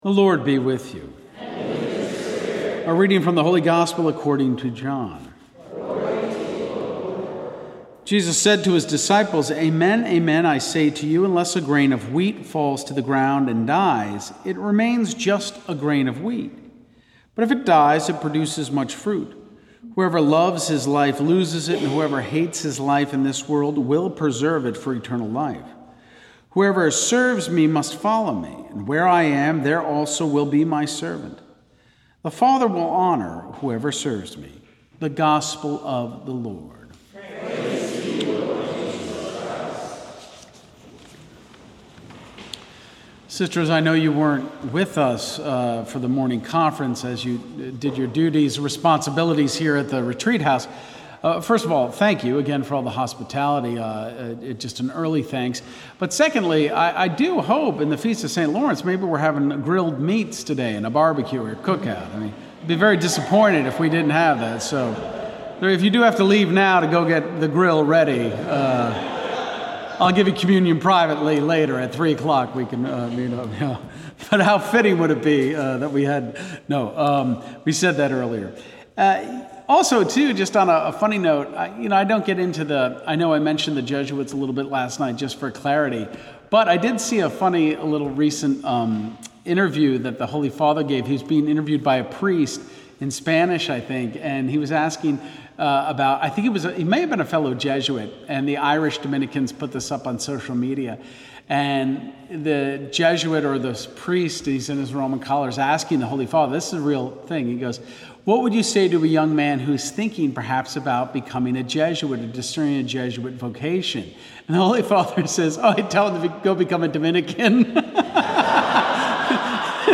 2024 Retreat – Saturday Homily (3 of 6)
This is the Saturday Homily.